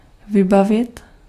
Ääntäminen
Ääntäminen France: IPA: /puʁ.vwaʁ/ Haettu sana löytyi näillä lähdekielillä: ranska Käännös Ääninäyte Verbit 1. vybavit Määritelmät Verbit Aviser à quelque chose , y donner ordre , suppléer à ce qui manque .